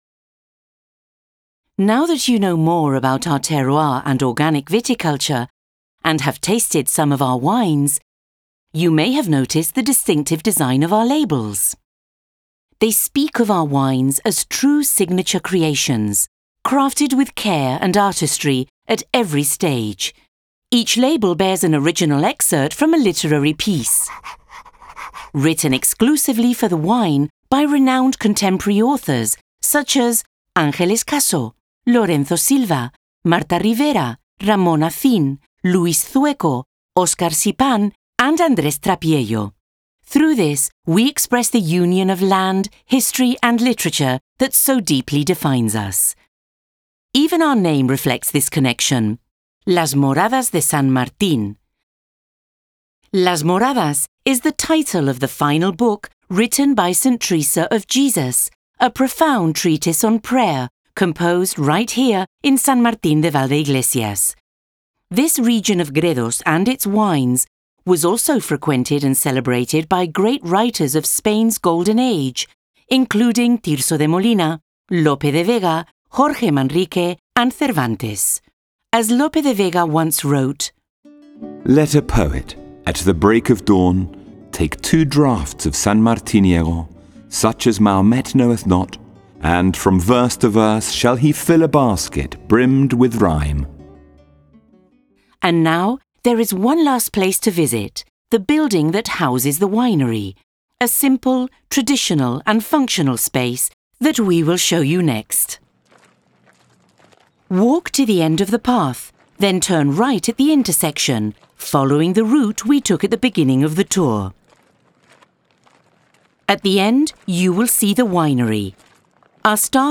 Self-guided tour: LITERATURE